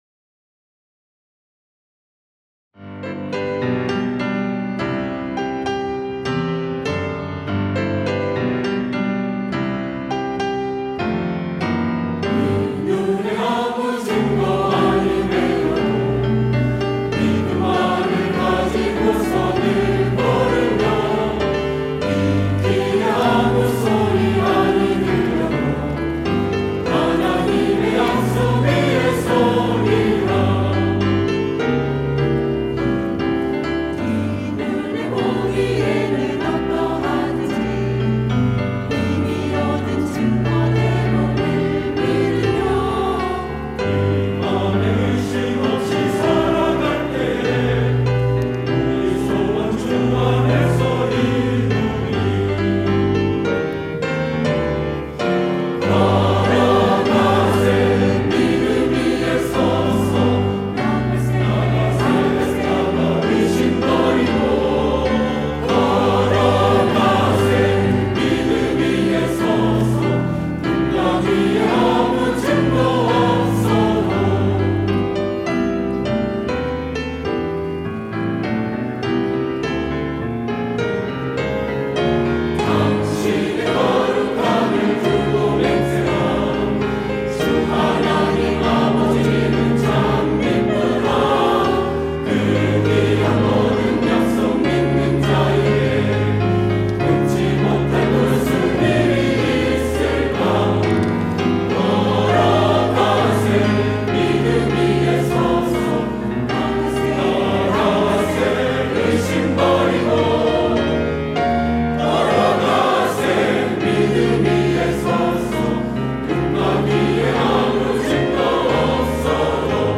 할렐루야(주일2부) - 이 눈에 아무 증거 아니 뵈어도
찬양대